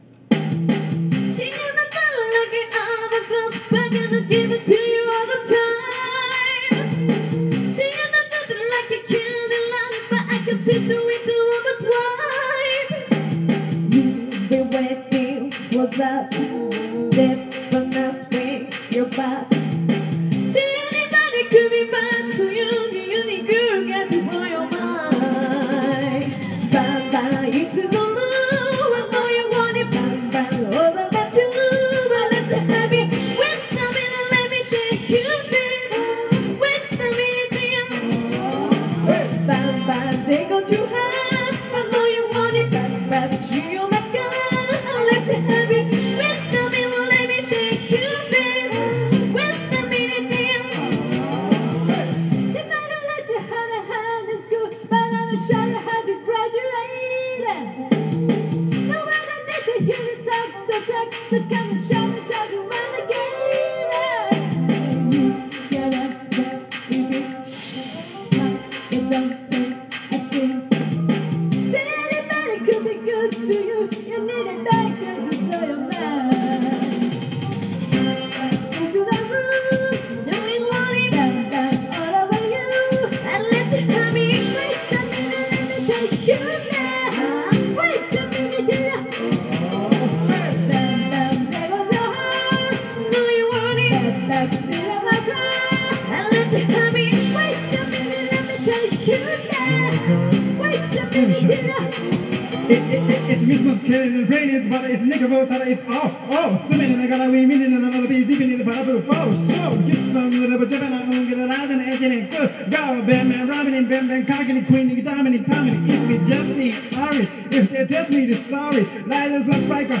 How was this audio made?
Duet & Chorus Night Vol. 12 TURN TABLE